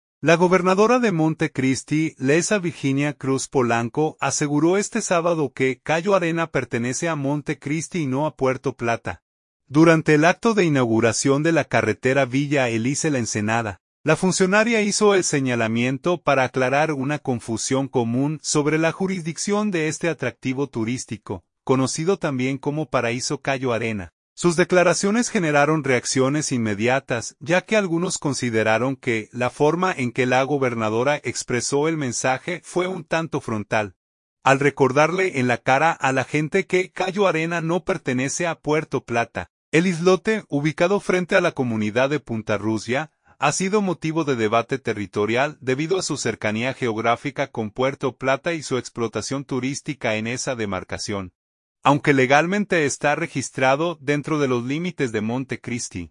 Monte Cristi, R.D. – La gobernadora de Monte Cristi, Leissa Virginia Cruz Polanco, aseguró este sábado que Cayo Arena pertenece a Monte Cristi y no a Puerto Plata, durante el acto de inauguración de la carretera Villa Elisa – La Ensenada.
Sus declaraciones generaron reacciones inmediatas, ya que algunos consideraron que la forma en que la gobernadora expresó el mensaje fue un tanto frontal, al “recordarle en la cara” a la gente que Cayo Arena no pertenece a Puerto Plata.